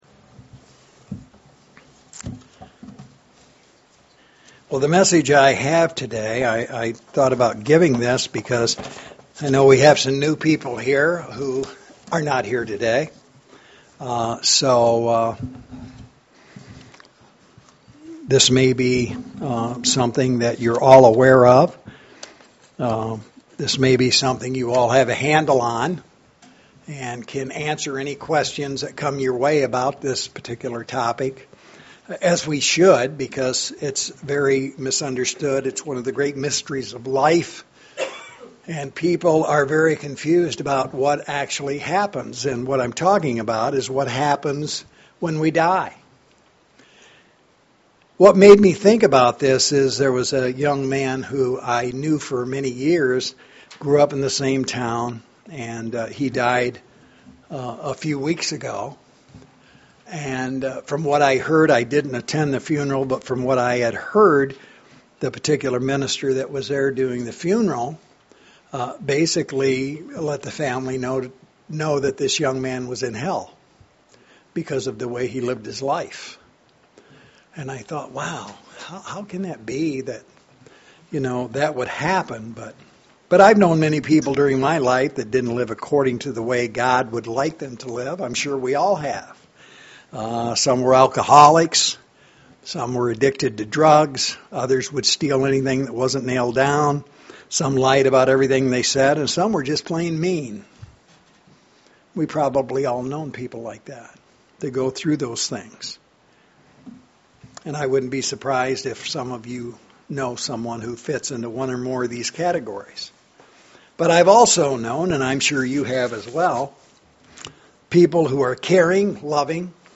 Given in Lansing, MI
UCG Sermon Studying the bible?